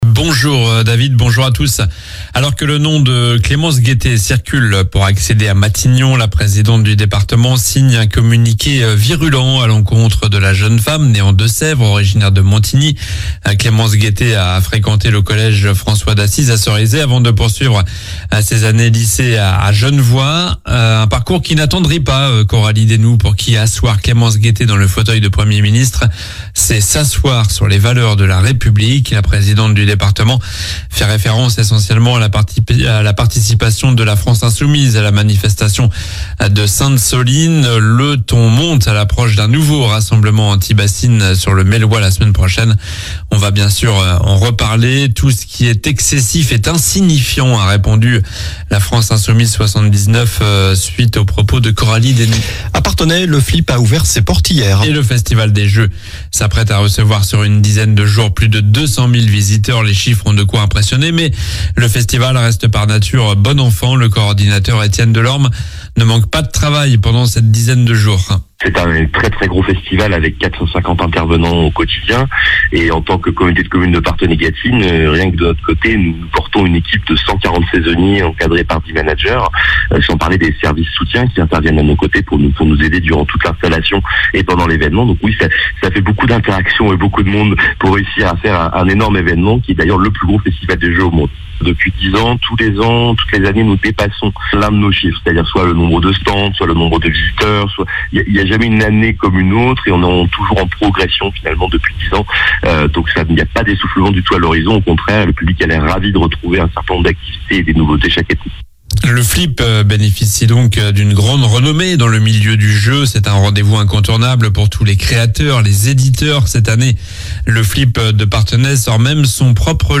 Journal du jeudi 11 juillet (matin)